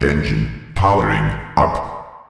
CyclopsEngineOn.ogg